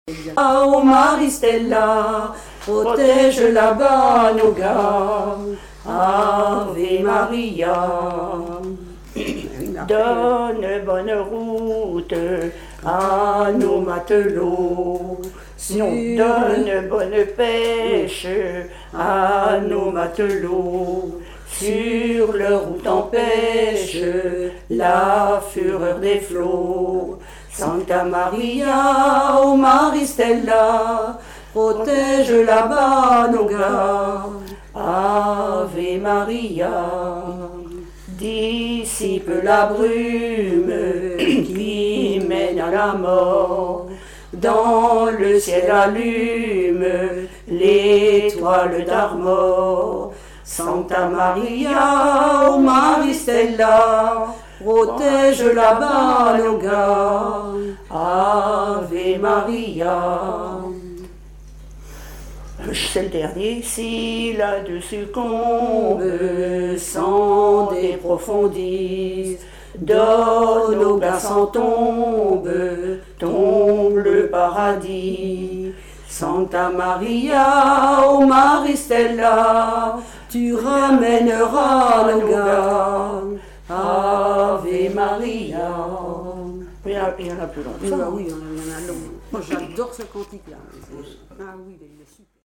Cantique du départ
Pièce musicale inédite